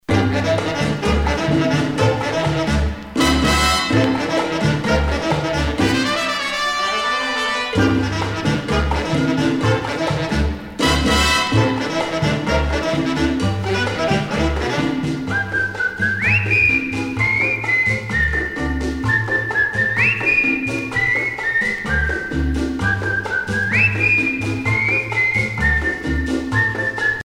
danse : cha cha cha